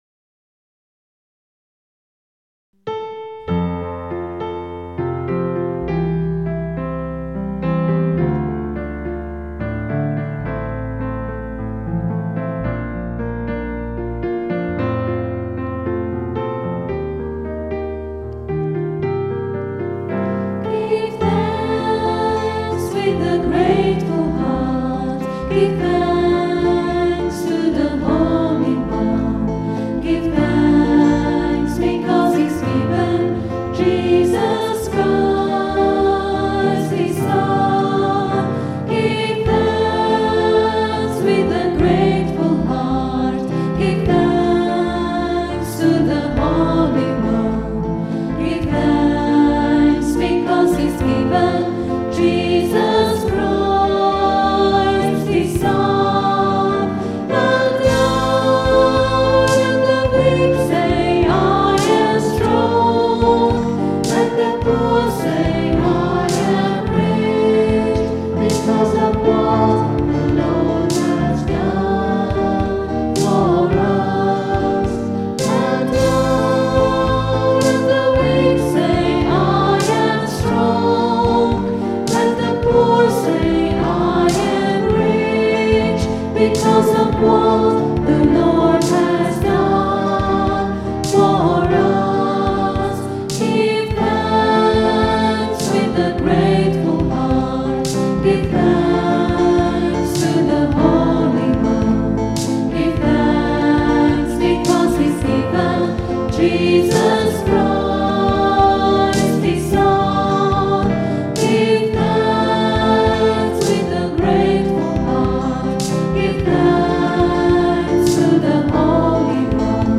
This well known and popular song was recorded at rehearsal on Tuesday 15th July 2008. Recorded on the Zoom H4 digital stereo recorder through a Behringer SL2442FX mixer. Sound editing and effects using Acoustica Mixcraft 4 audio processing software.